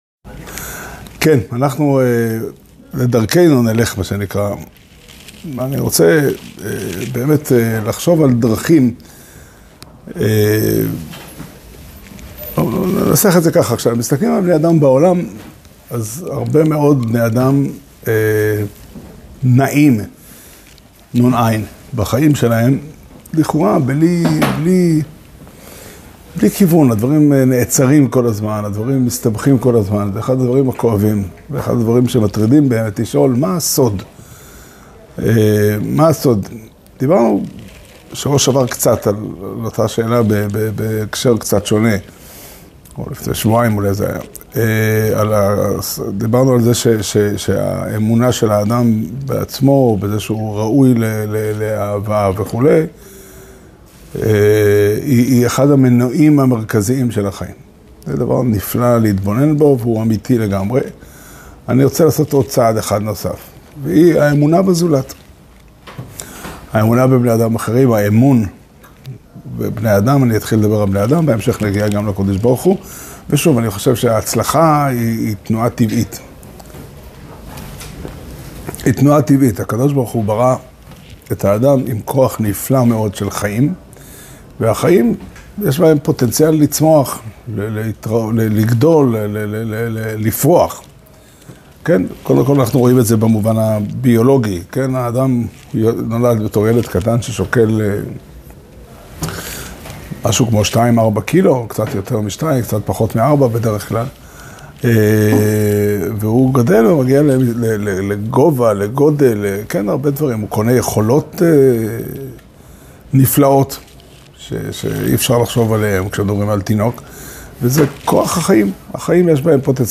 שיעור שנמסר בבית המדרש פתחי עולם בתאריך ב' טבת תשפ"ה